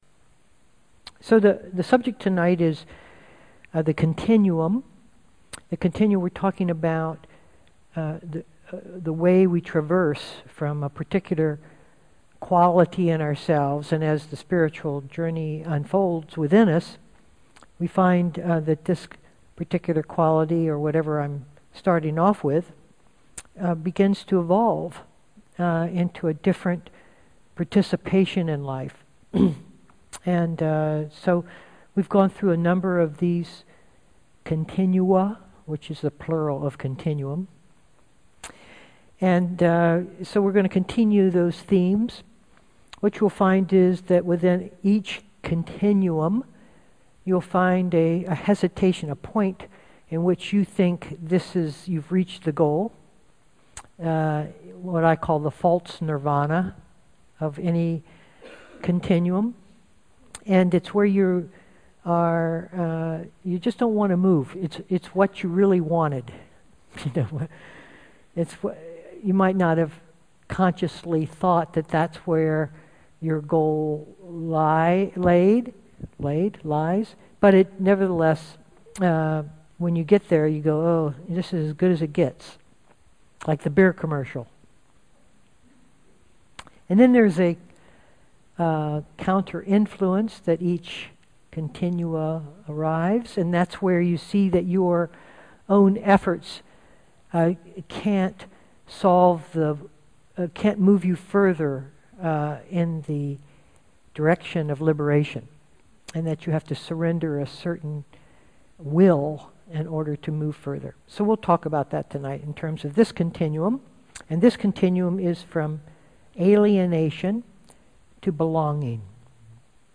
2015-10-20 Venue: Seattle Insight Meditation Center Series